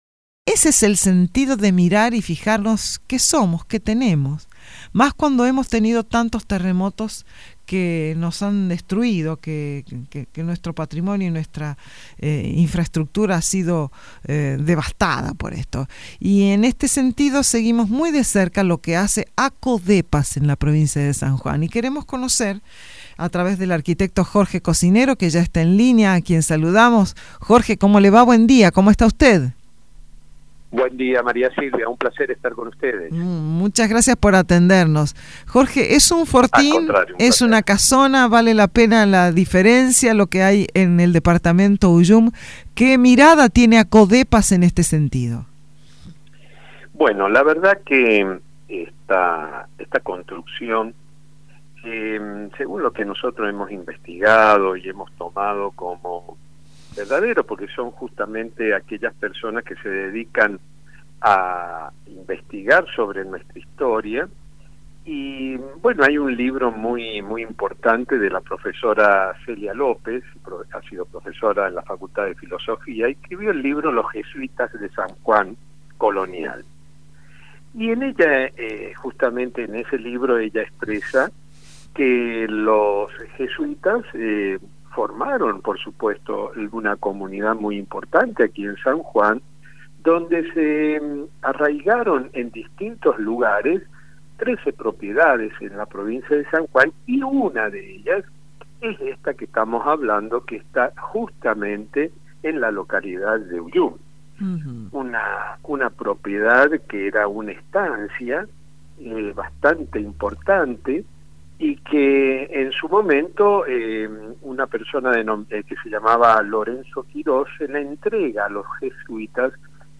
Escucha la nota completa: